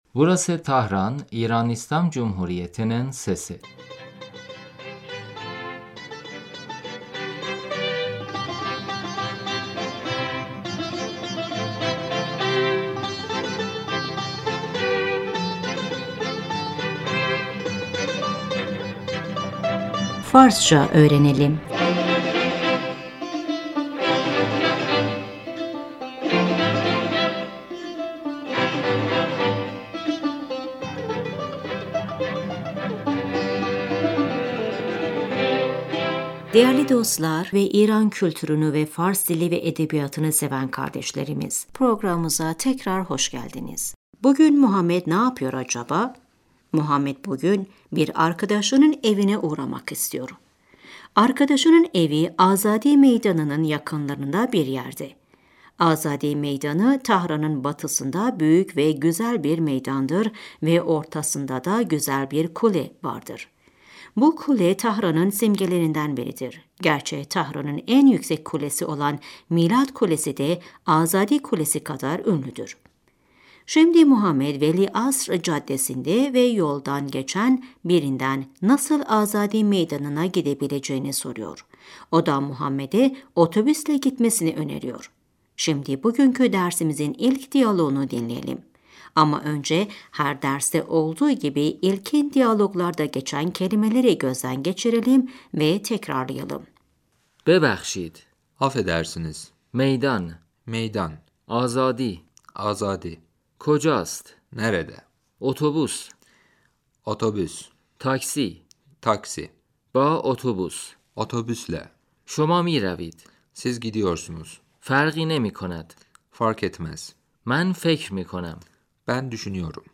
Şimdi dersimizin ilk diyaloğunu dinleyin ve tekrarlayın. در خیابان ، صدای عبور و مرور خودروها Caddede, trafik sesi محمد - ببخشید آقا .